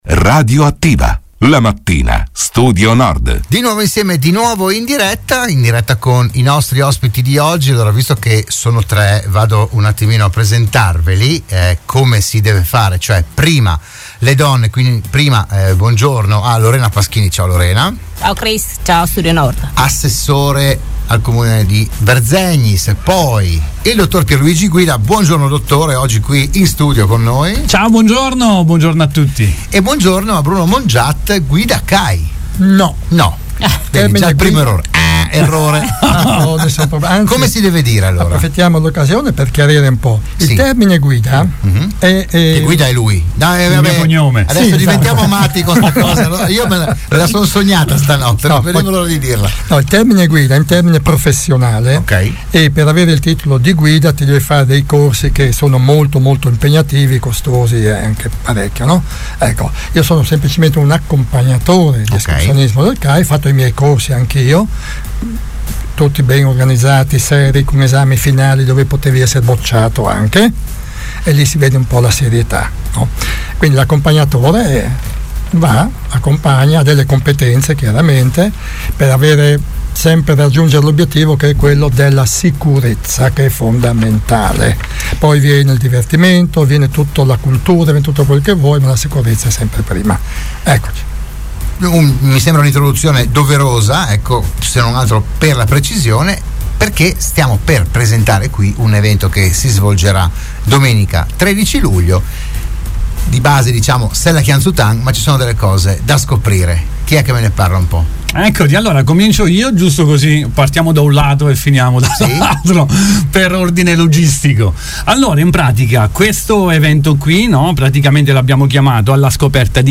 Dell'iniziativa hanno parlato a Radio Studio Nord